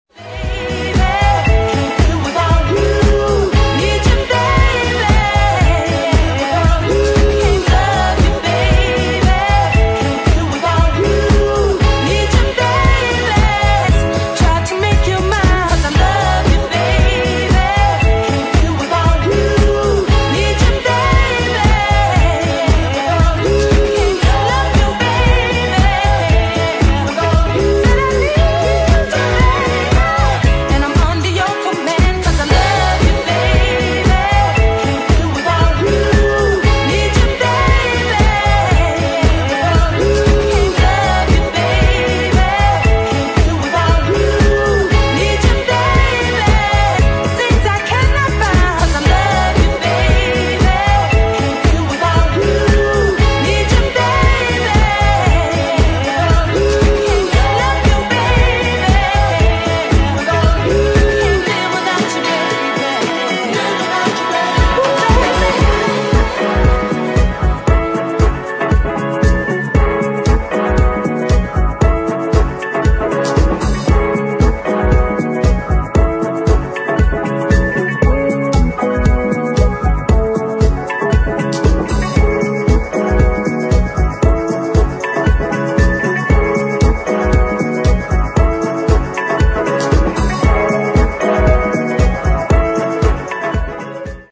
[ DISCO / EDIT ]
古典的なディスコ・フィーリングとシャッフル・ビートの